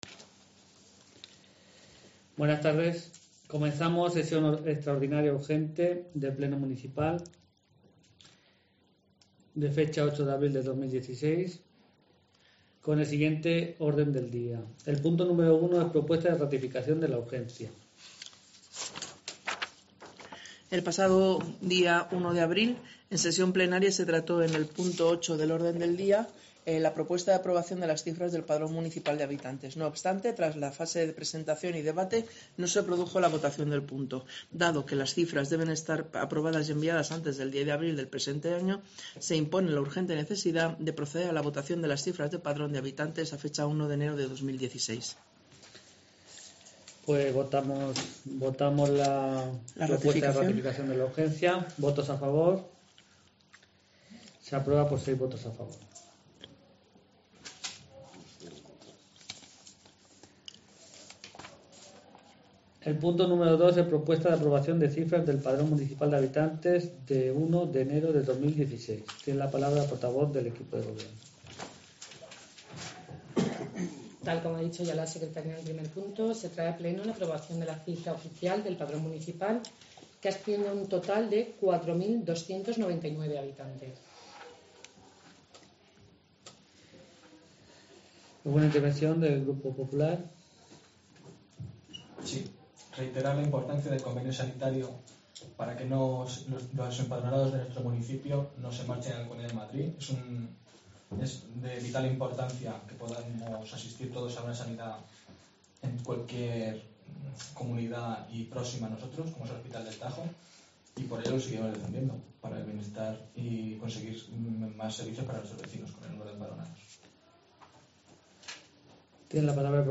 El viernes 8 de abril se celebró Sesión Extraordinaria Urgente del Pleno Municipal del Ayuntamiento de Ontigola: